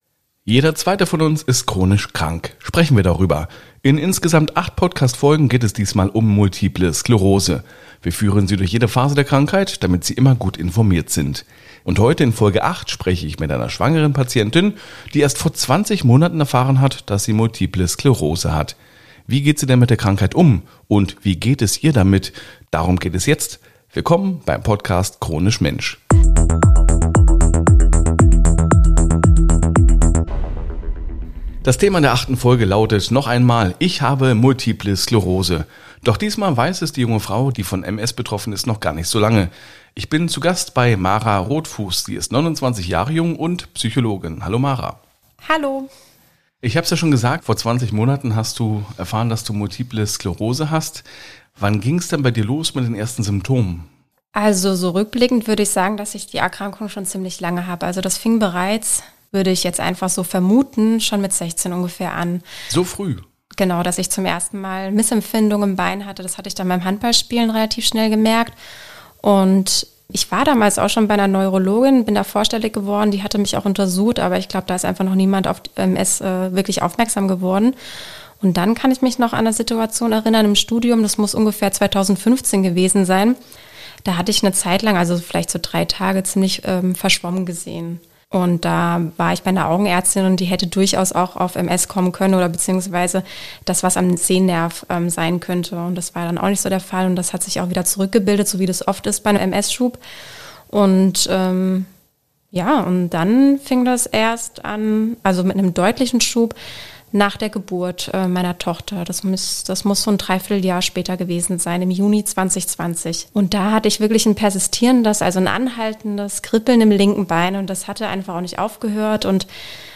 Diesen und weiteren Fragen stellt sich die junge Patientin.